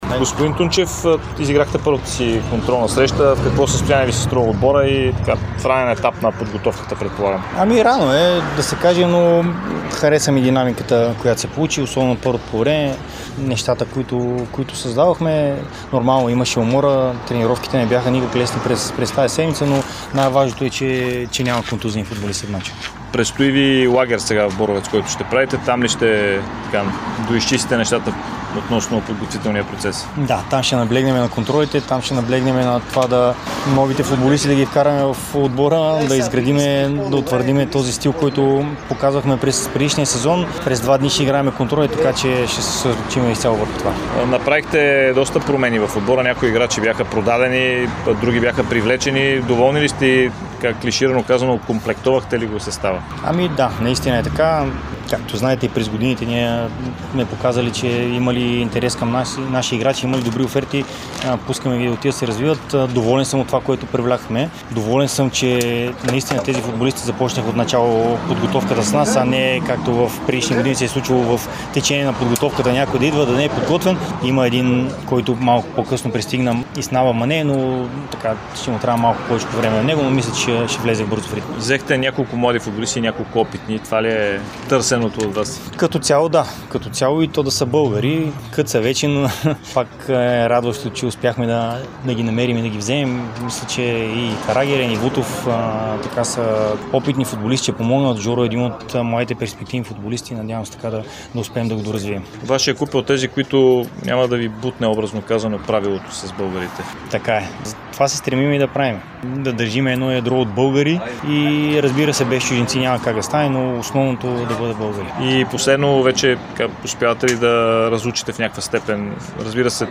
Александър Тунчев говори пред Дарик и dsport, след като неговият Арда подчини с 4:0 втородивизионния Спортист Своге в контрола. Тунчев заяви отново, че е доволен от селекцията, а клубът е показал, че когато дойде подходяща оферта, не спира играчите да се развиват.